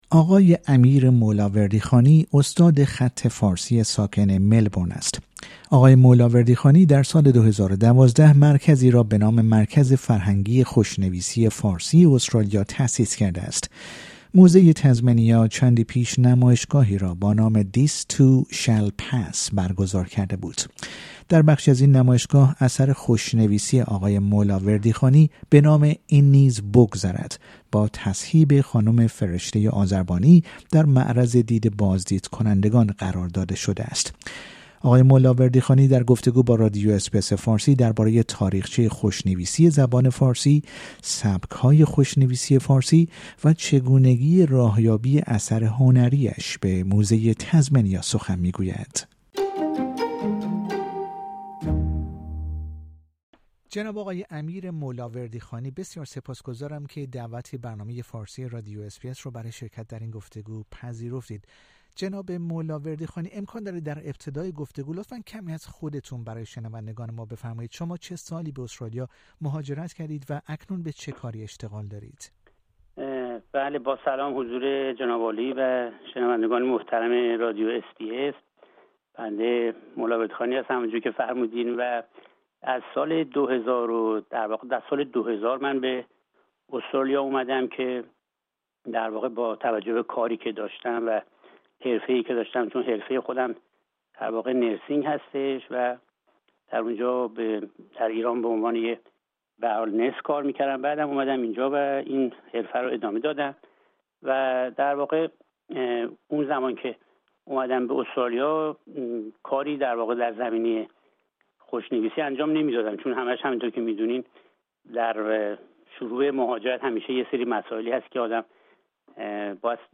در گفتگو با رادیو اس بی اس فارسی